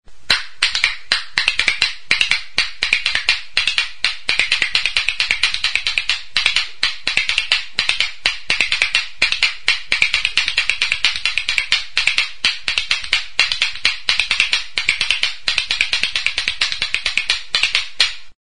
Idiophones -> Struck -> Indirectly
Recorded with this music instrument.
GOILAREAK; CUCHARAS
Eskuz egindako ezpelezko bi goilare dira.
WOOD; BOX